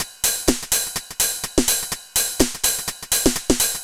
Index of /musicradar/retro-house-samples/Drum Loops
Beat 09 No Kick (125BPM).wav